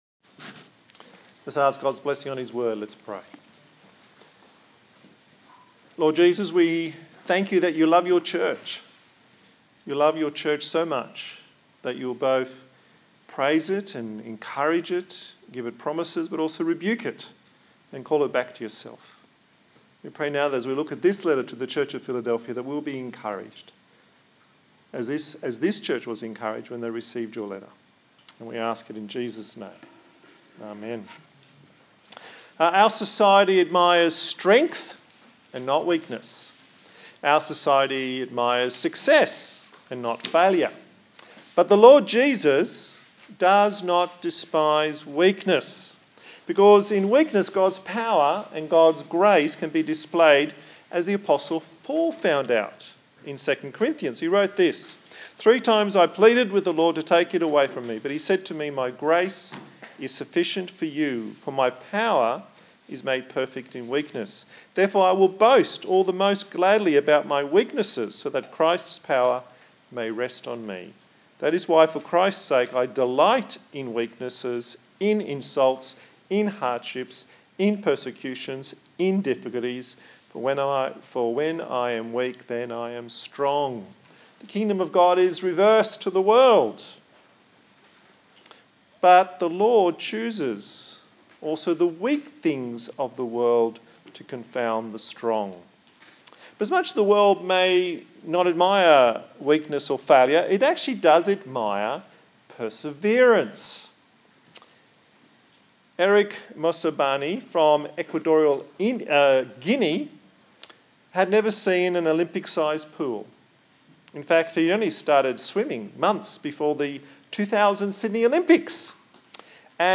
A sermon in the series on the book of Revelation